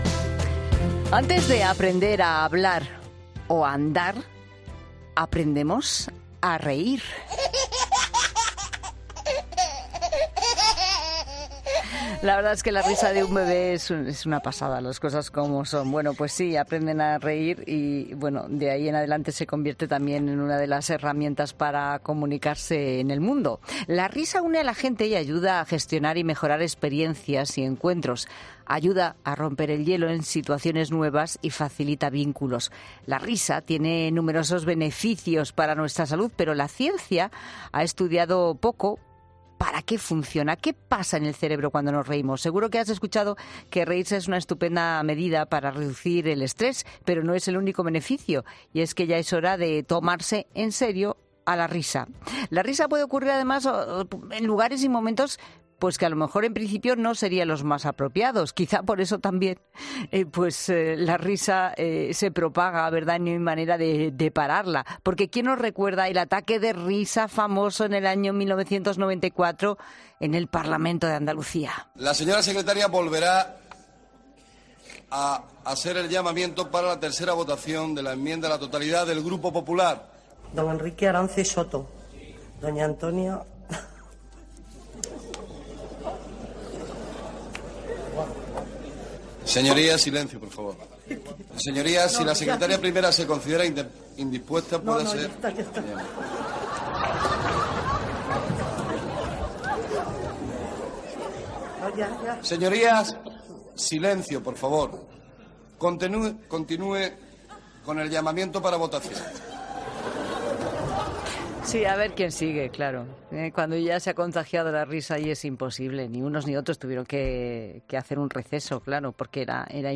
Hablamos de la risa.